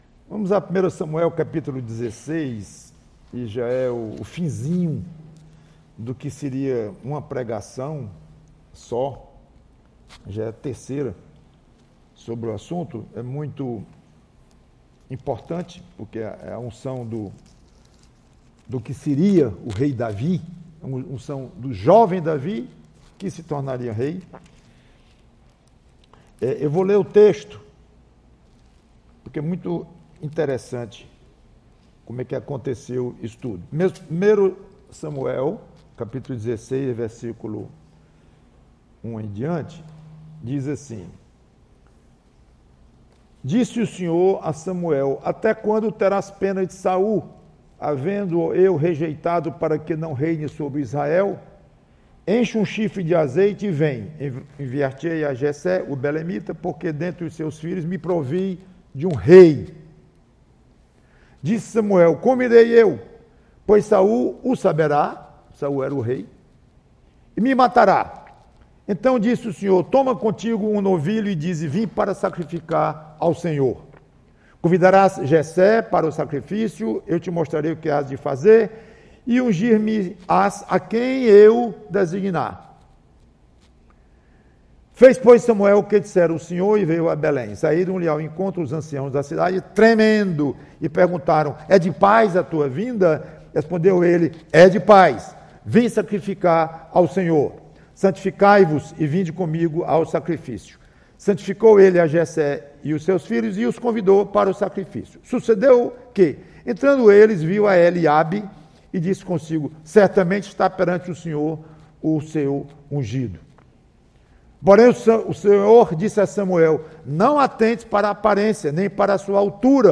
PREGAÇÃO